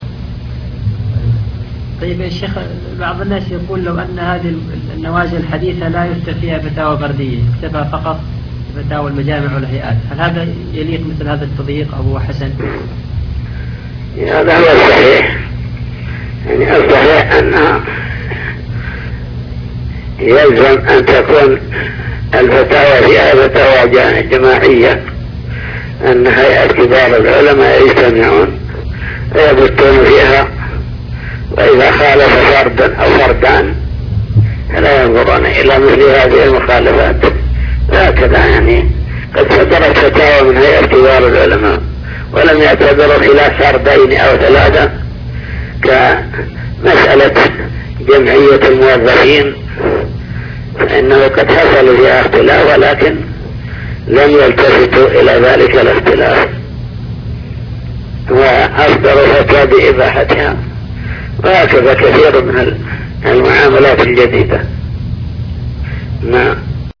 المكتبة الصوتية  تسجيلات - لقاءات